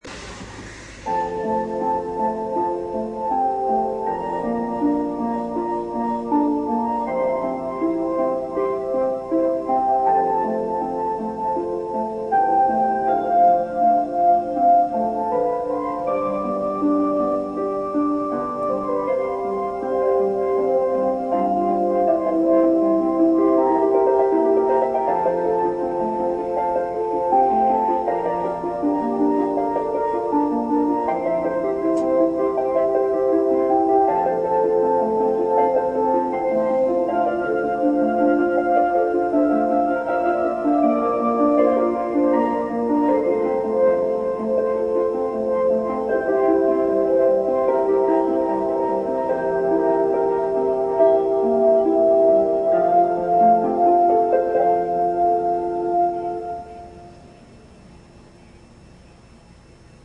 愛西市立田地区（旧・海部郡立田村）では毎日、朝と夕方の決まった時刻に地区内の防災無線からメロディが流れます。
夕方は17時ちょうどに、約60秒のメロディが流れます。
立田地区 夕方のチャイム
06.03.03 (Fri) 17:00 立田南部地区防災コミュニティセンター付近で録音